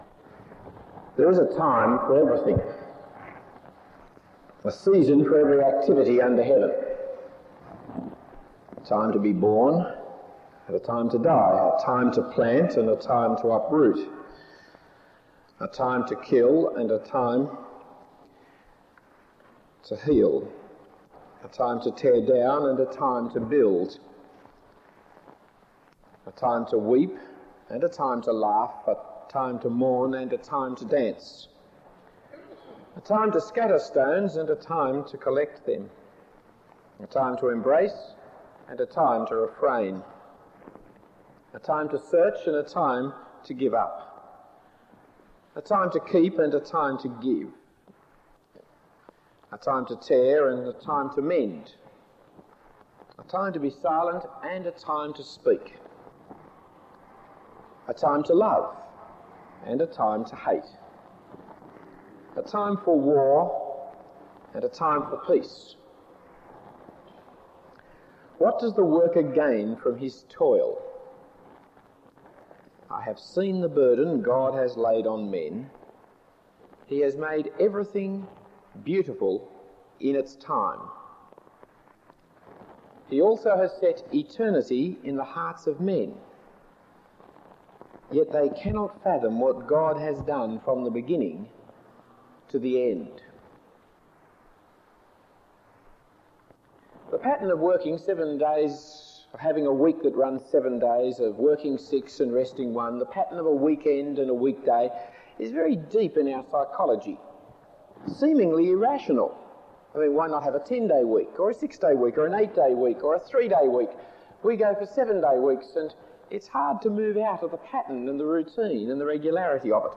Talk 2 of 3 in the series Genesis 1988 St Matthias.